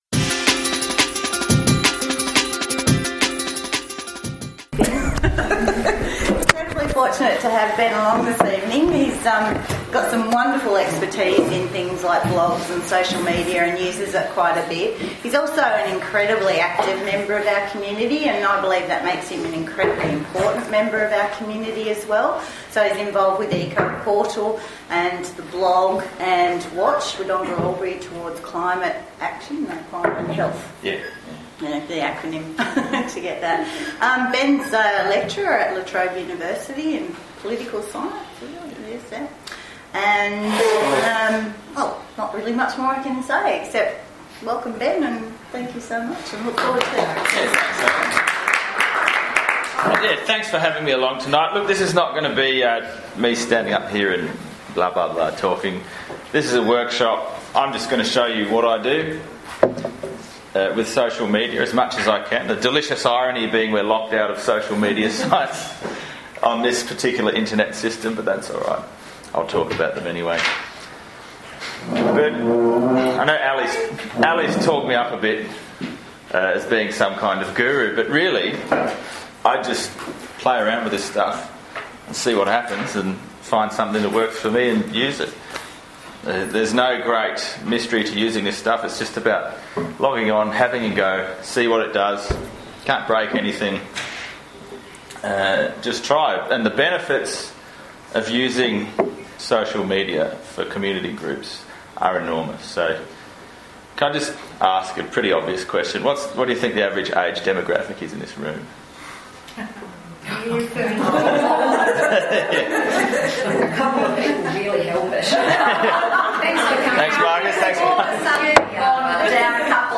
Social Media 101 for Community Groups: Presentation to Friends of Willow Park